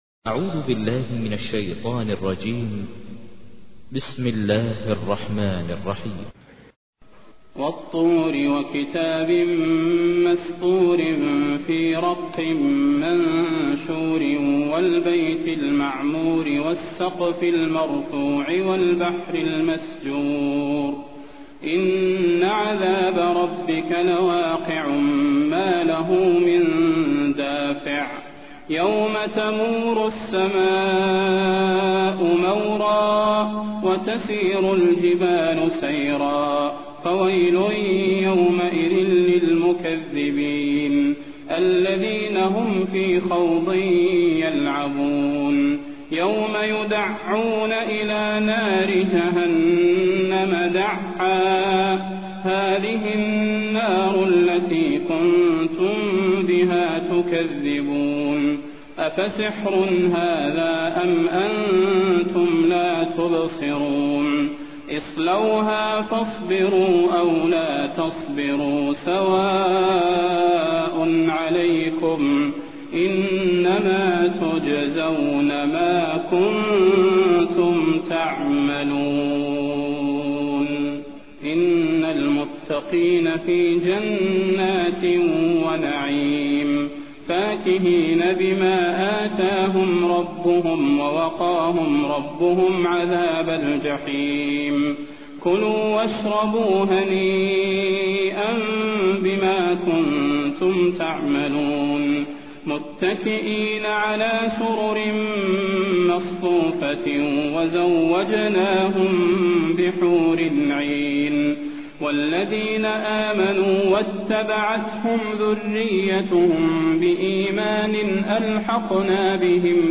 تحميل : 52. سورة الطور / القارئ ماهر المعيقلي / القرآن الكريم / موقع يا حسين